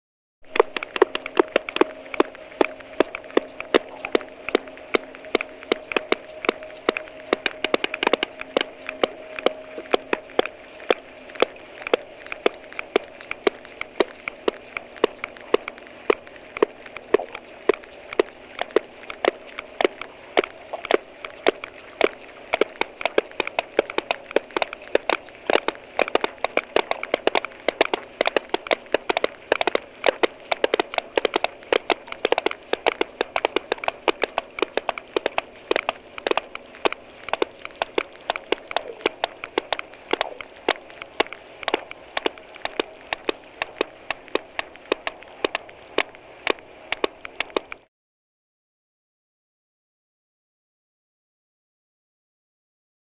На этой странице собраны их уникальные вокализации: от низкочастотных стонов до резких щелчков эхолокации.
Звуки щелчков кашалота в глубинах океана (эхолокация)